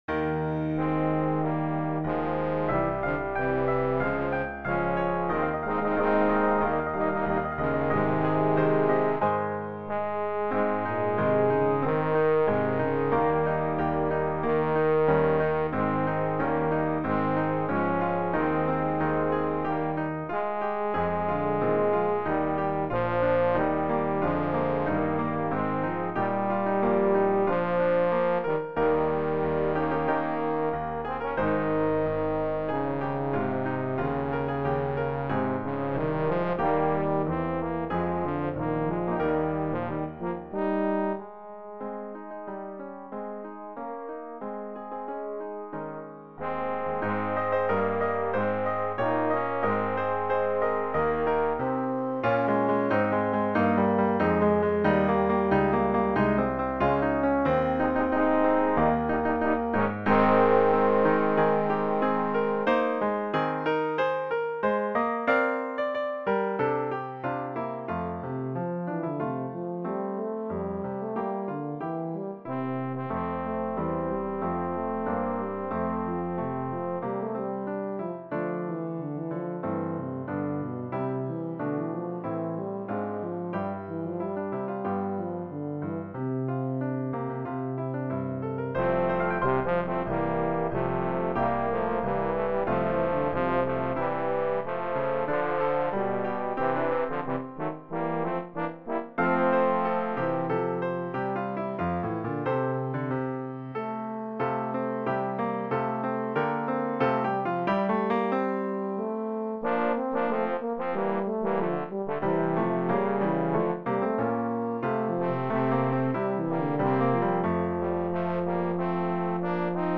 Ab, F
trio: trombone
Song (ternary)
3 trombones with hn and BHTC subs) and piano